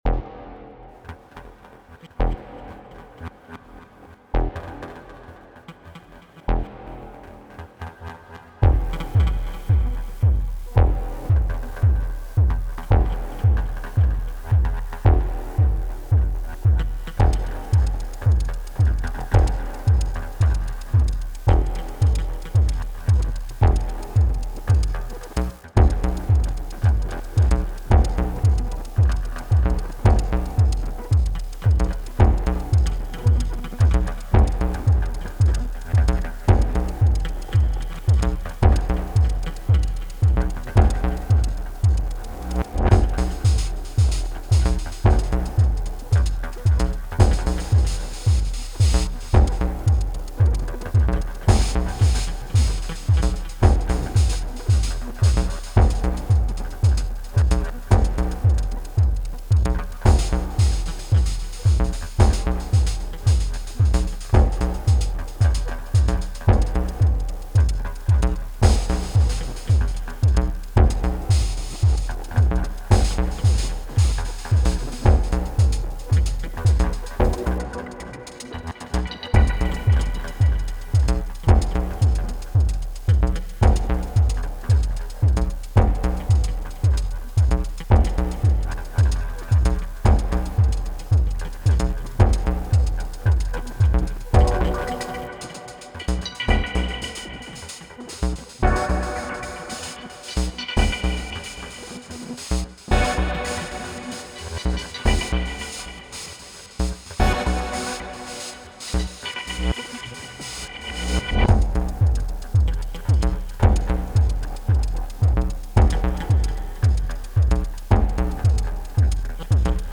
Here’s the first thing I made with 6x instances of it and a bit of Arturia EFX Fragments.